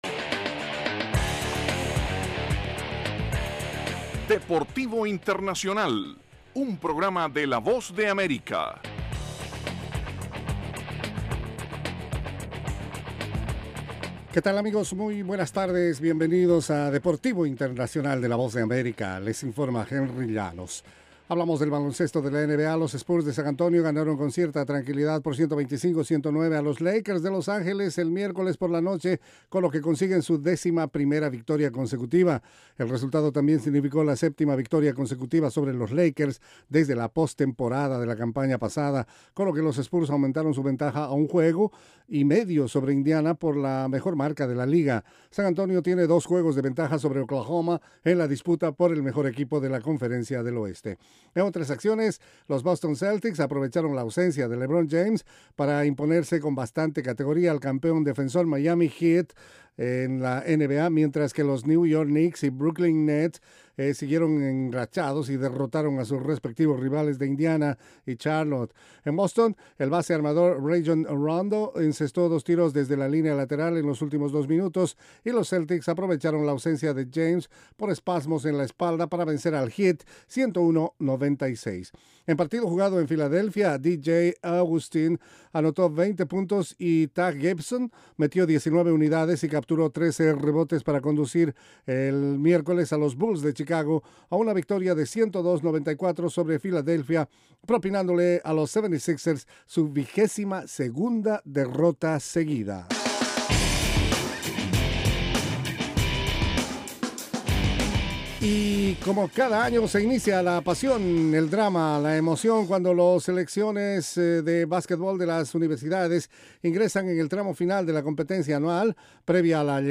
presenta las noticias más relevantes del mundo deportivo desde los estudios de la Voz de América.